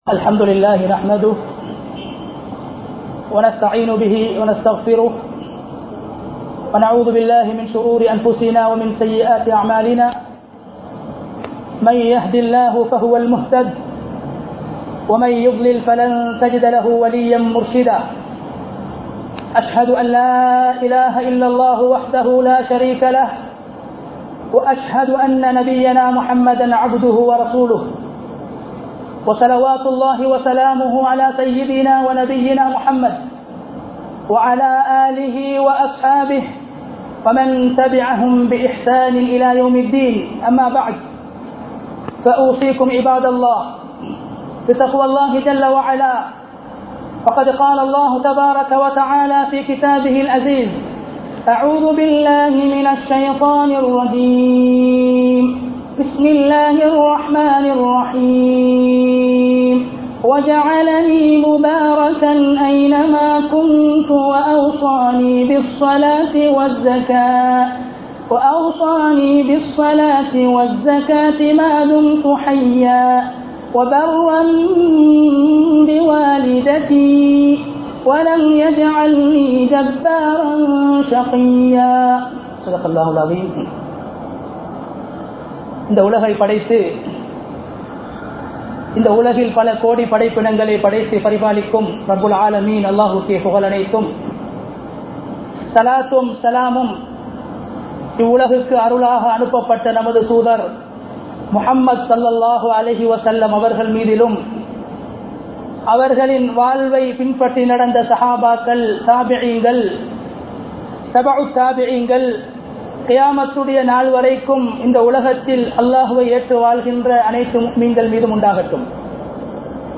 பாக்கியசாலியின் 03 பண்புகள் (3 Sighns of a Fortunated Person) | Audio Bayans | All Ceylon Muslim Youth Community | Addalaichenai
Dehiwela, Kawdana Road Jumua Masjidh 2020-08-21 Tamil Download